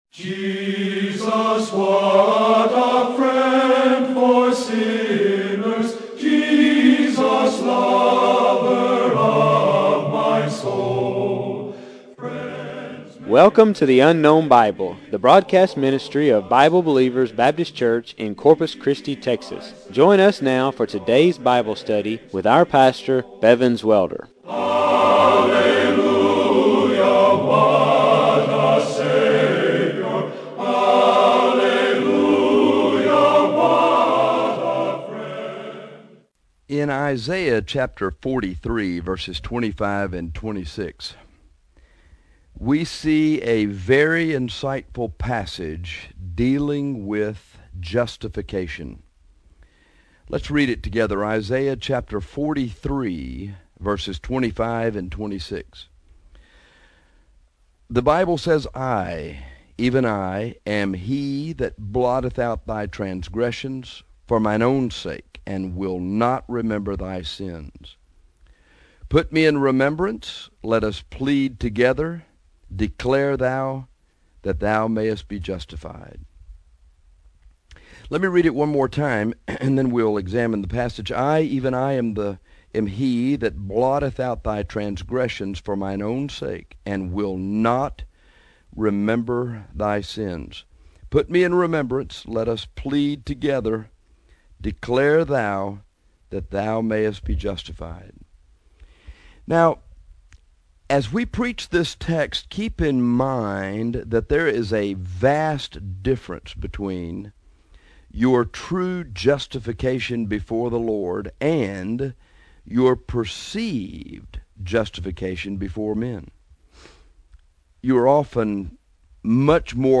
As we preach this text, keep in mind that there is a vast difference between your true justification before the Lord and your perceived justification before men.